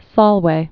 (sŏlwā)